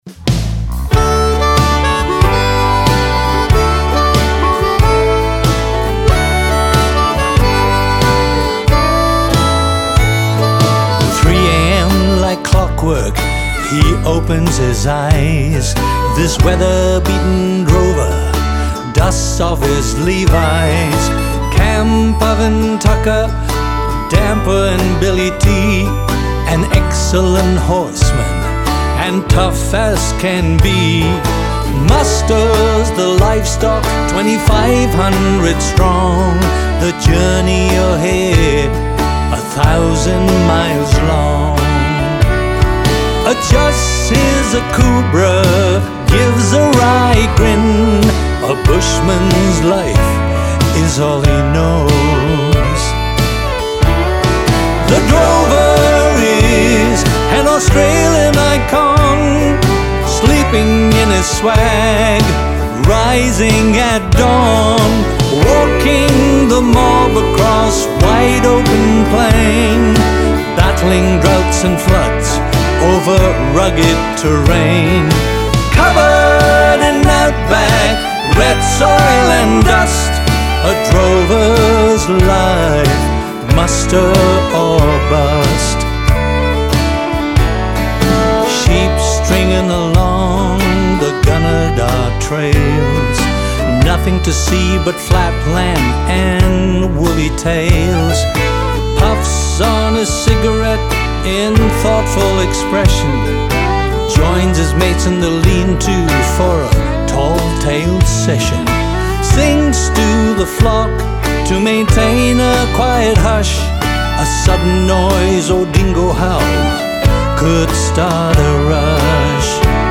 story song